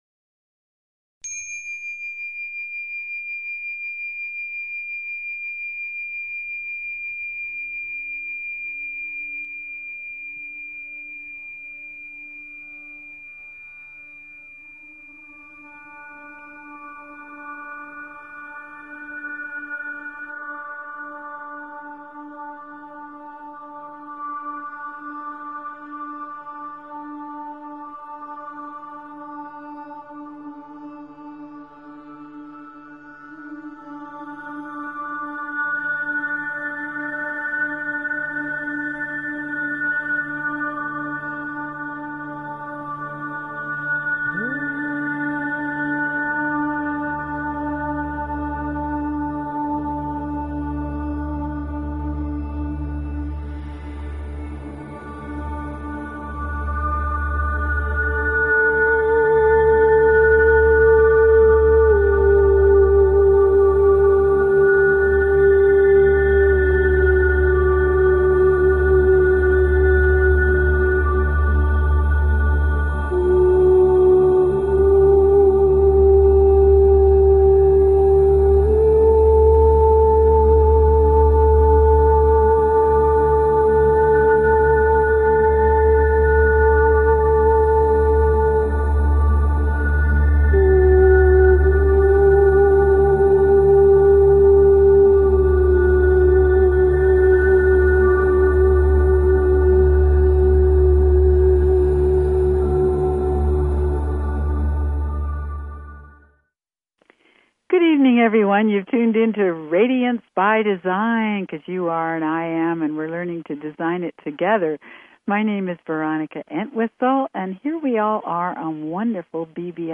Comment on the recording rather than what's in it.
The show offers you a much needed spiritual tune up – gives you the means to hold your own as you engage the crazy dynamics that occupy our ever changing planet. Radiance By Design is specifically tailored to the energies of each week and your calls dictate our on air discussions.